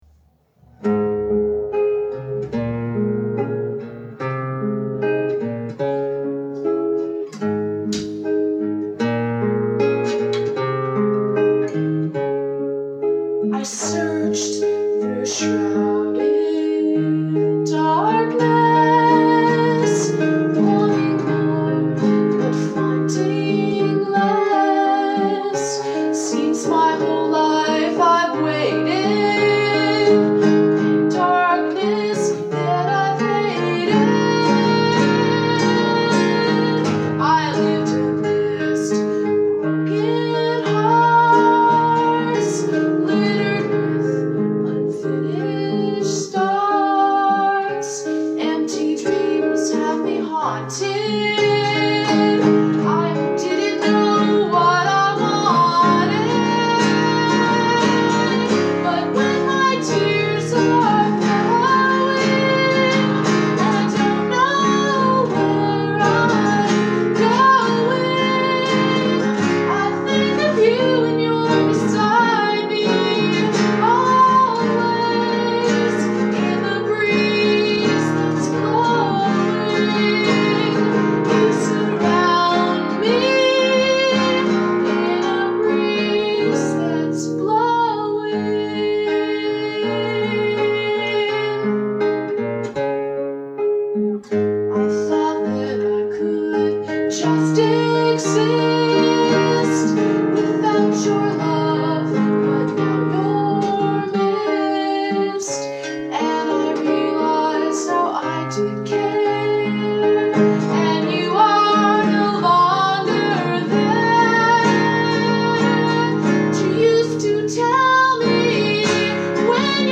There were at least 30 of them and I was lucky I had an old cassette with 20 songs recorded in 1980.
It was courageous, but the first song I shared on my blog was the 1980 recording of “Beside Me Always.”